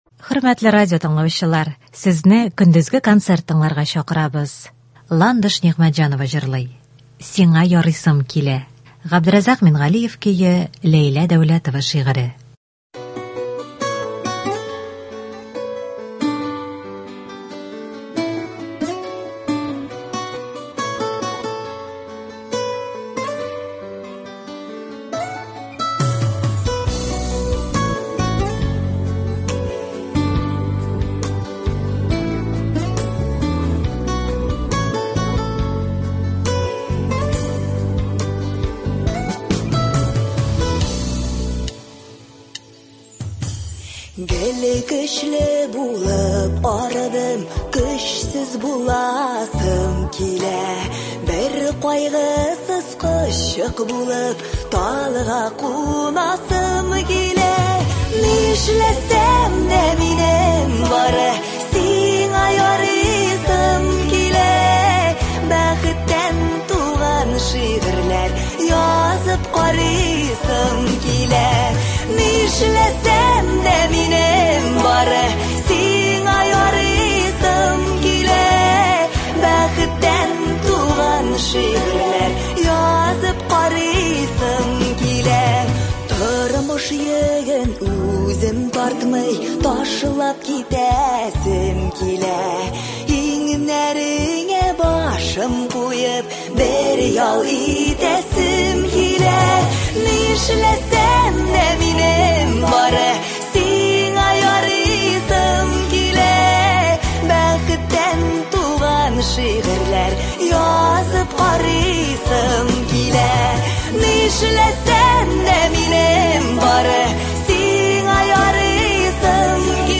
Көндезге концерт.